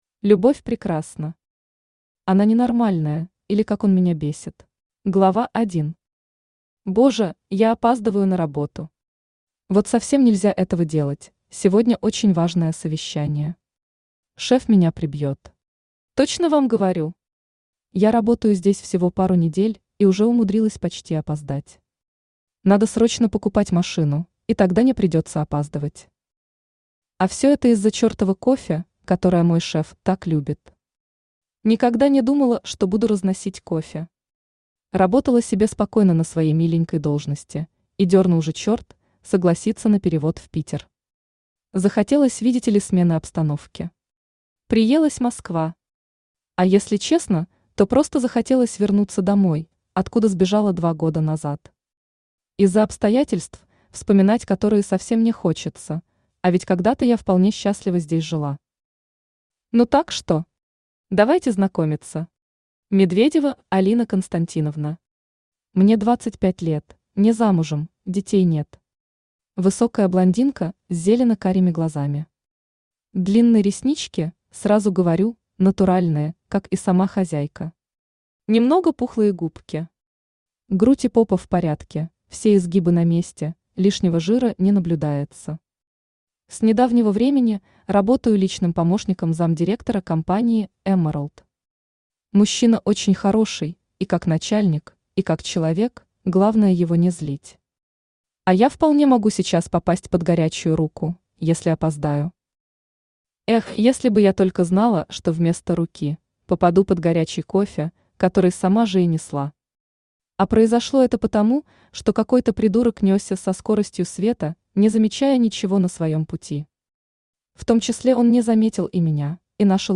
Аудиокнига Она ненормальная, или Как он меня бесит | Библиотека аудиокниг